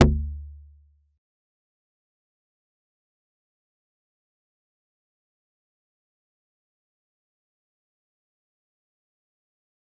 G_Kalimba-G1-pp.wav